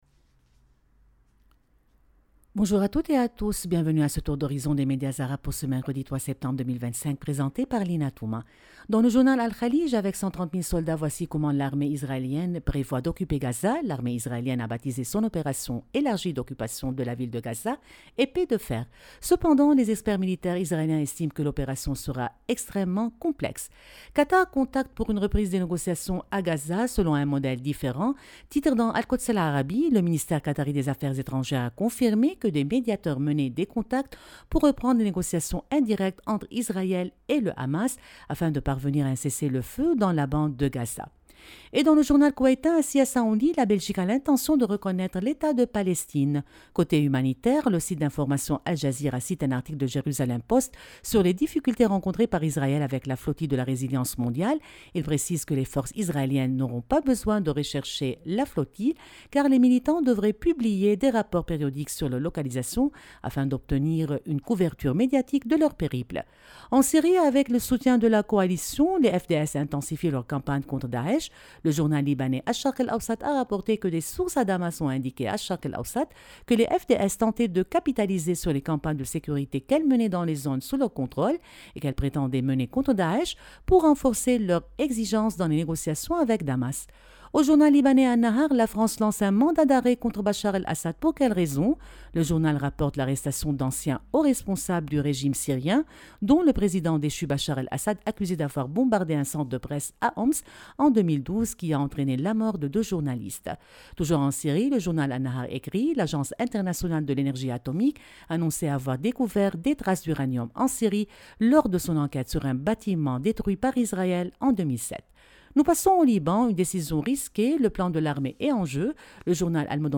Revue de presse des médias arabes du 3 septembre 2025